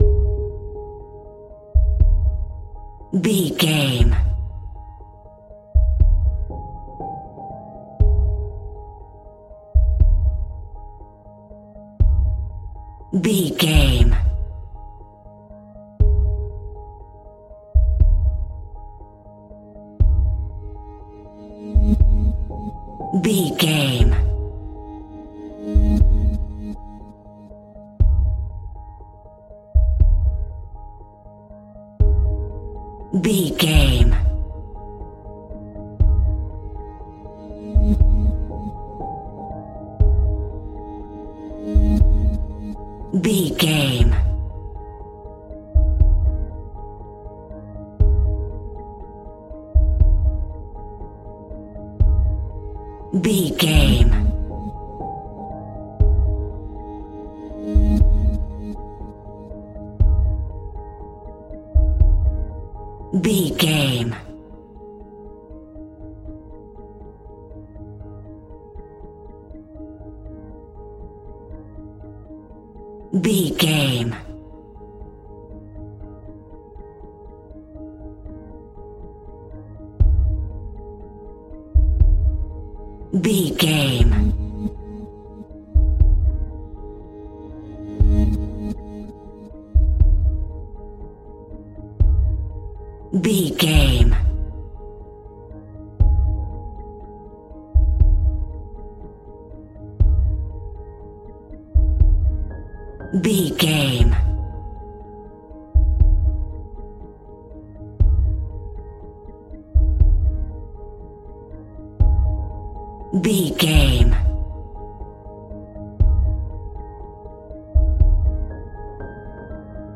Aeolian/Minor
scary
ominous
dark
haunting
eerie
percussion
synthesizer
mysterious